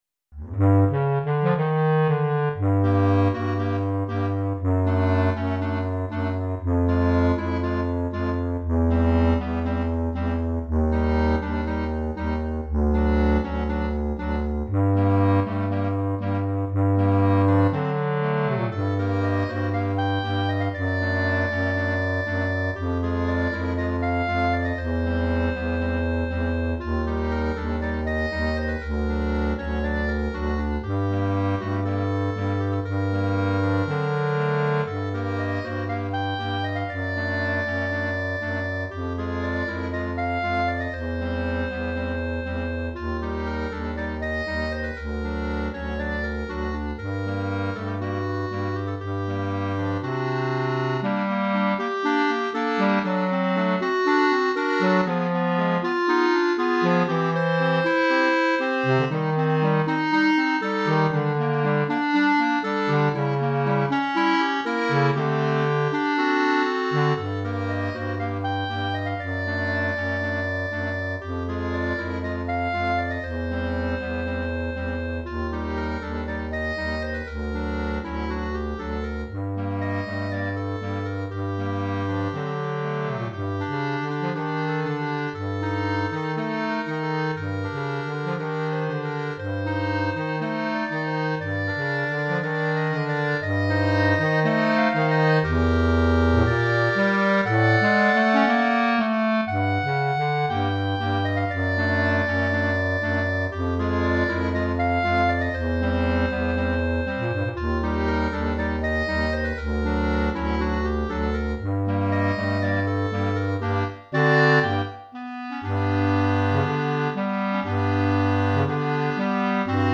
3 Clarinettes Sib et Clarinette Basse